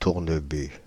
Tournebu (French pronunciation: [tuʁnəby]
Fr-Tournebu.ogg.mp3